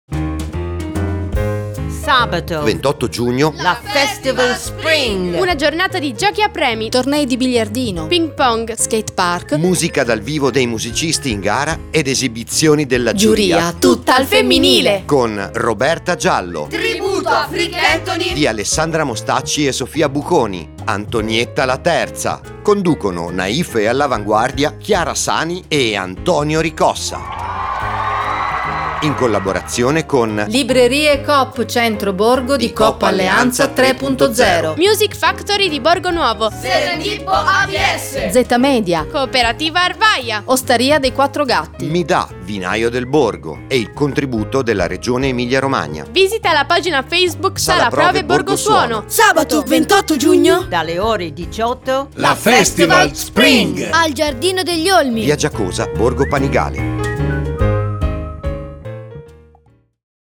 SPOT audio